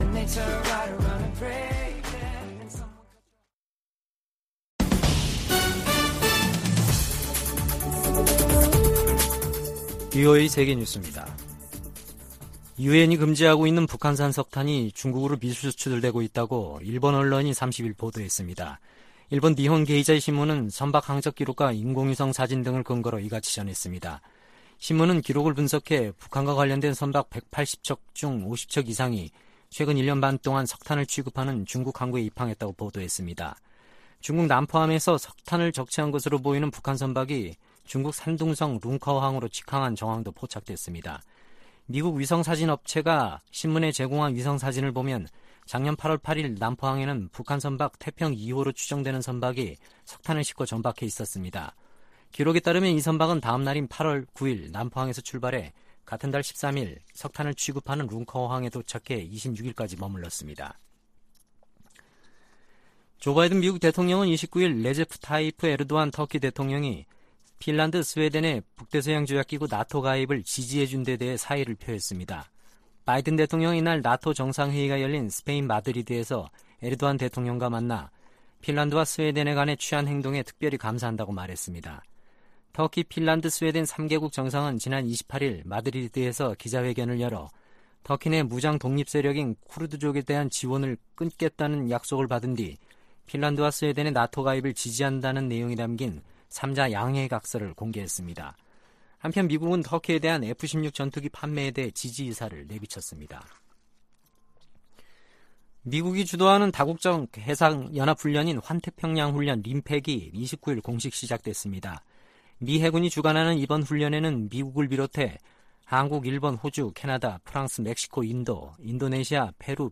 VOA 한국어 아침 뉴스 프로그램 '워싱턴 뉴스 광장' 2022년 7월 1일 방송입니다. 백악관은 조 바이든 대통령이 한국·일본 정상과 협력 심화를 논의한 역사적인 회담을 열었다고 발표했습니다. 미 공화당 상원의원들은 나토가 중국과 러시아의 위협에 동시에 대응하도록 할 것을 바이든 대통령에게 촉구했습니다. 냉각된 한일관계에 개선은 대북 억제와 중국 견제를 위해 중요하다고 미국 전문가들이 진단했습니다.